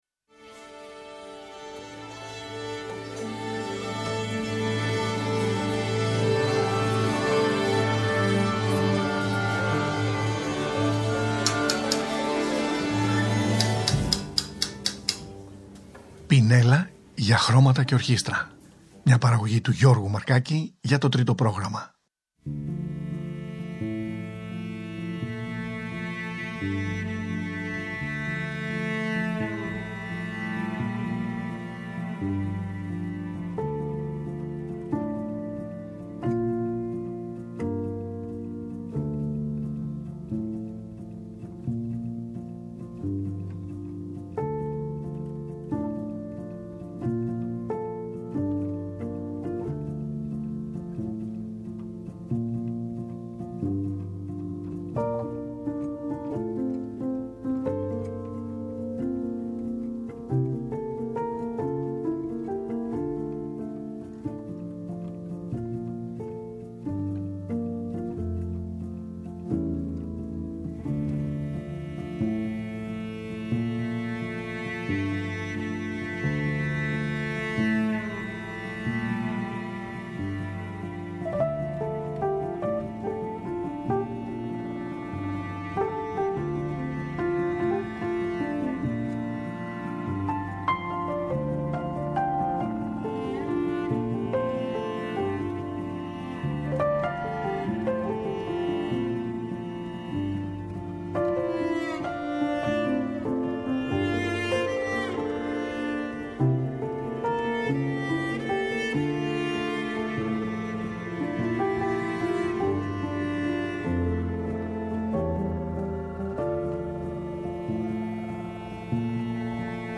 Σήμερα από την συχνότητα του Γ’ Προγράμματος της ΕΡΤ ταξιδεύουμε στο Katowice της Πολωνίας για να συναντήσουμε ένα απο τα πιο δυναμικά σχήματα, όχι μόνο της πατρίδας τους, της Πολωνίας αλλά ολάκερης της Ευρωπαικής σύγχρονης μουσικής που πατάει πάνω στην παράδοση, πρόκειται για το 5μελές σχήμα εγχόρδων Volosi απο την περιοχή της Σιλέσια της ΝΔ Πολωνίας που συνάντησα το 2017.
Εγώ έπλαθα μια μικρή ιστορία , την οποία διηγούμουν και εκείνοι αποτύπωναν την φαντασία τους με τα όργανα τους μαζί σαν σχήμα Volosi.